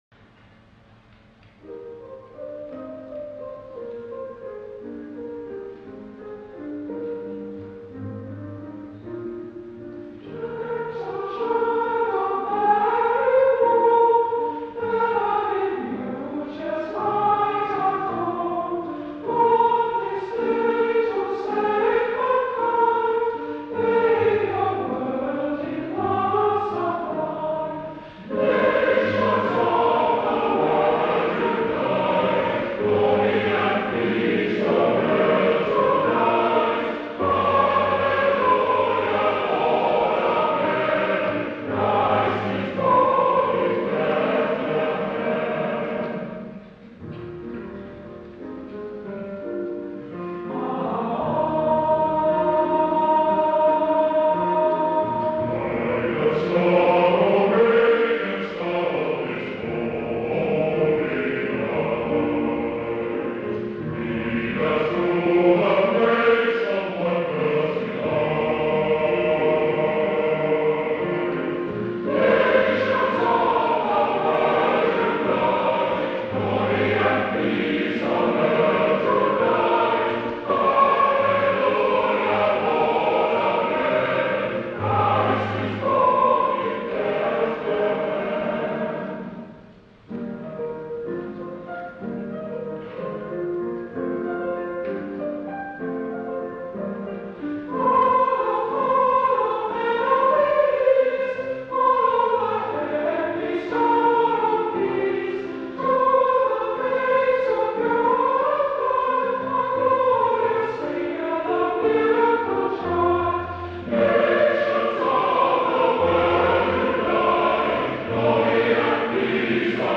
As it’s that Christmas time of year again, I give you Miracle Child for your festive entertainment. It’s a bit ropey as it was recorded on an old cassette recorder at the back of the hall.
Imagine his pleasure and surprise when, two decades on, he discovered that they are once again performing one of his 20 th Century pieces at a 2011 Christmas service.